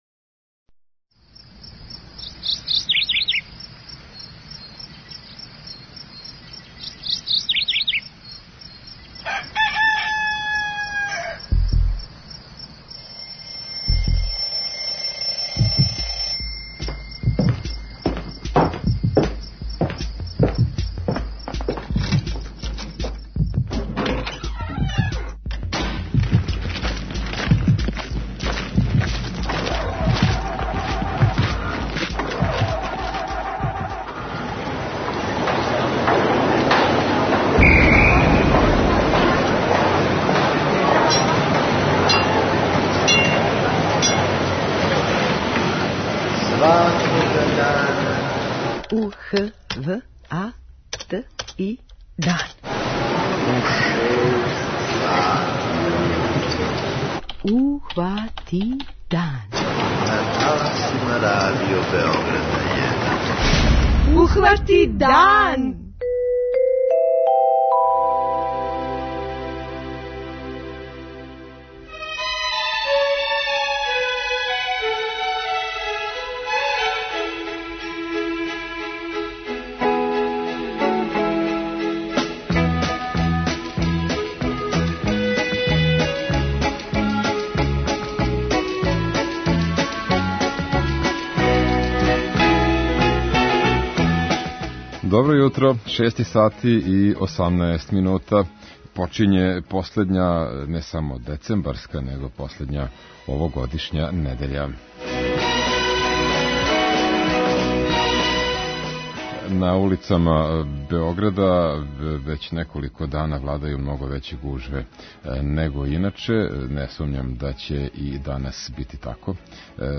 Питаћемо и слушаоце да нам пренесу своја мишљења.
преузми : 29.34 MB Ухвати дан Autor: Група аутора Јутарњи програм Радио Београда 1!